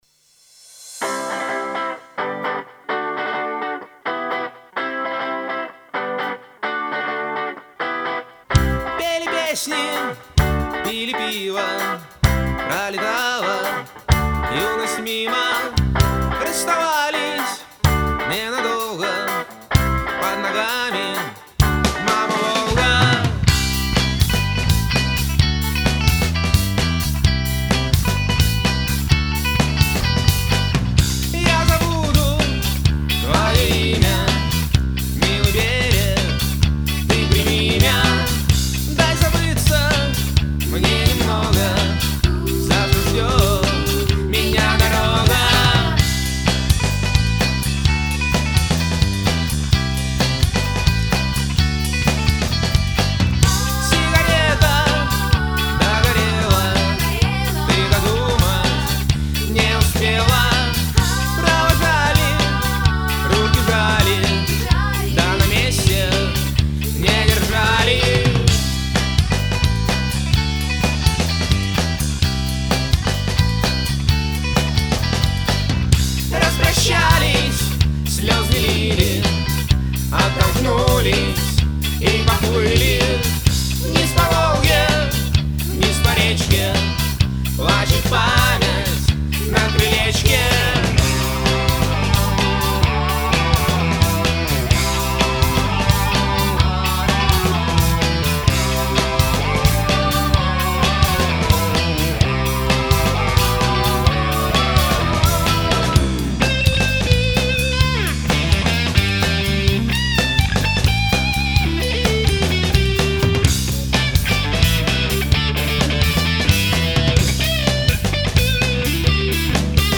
гитары
бэк-вокал